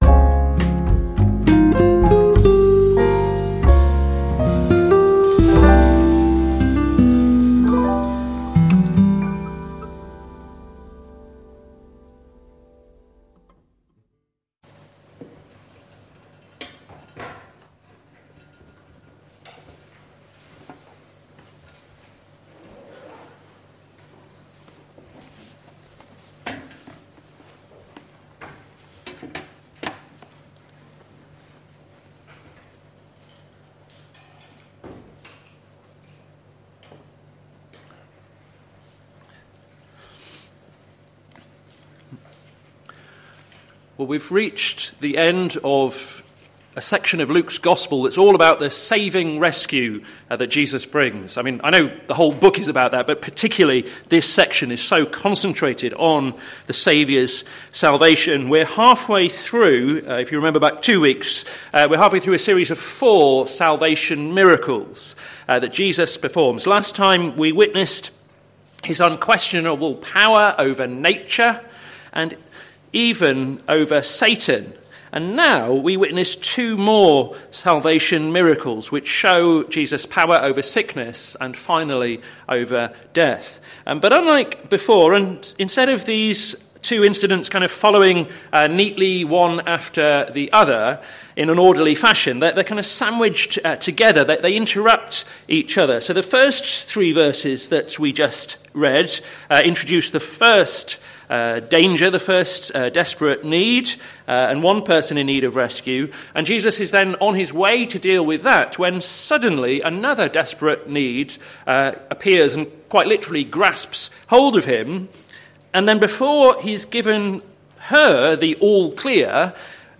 Sermon Series - To Seek and to Save the Lost - plfc (Pound Lane Free Church, Isleham, Cambridgeshire)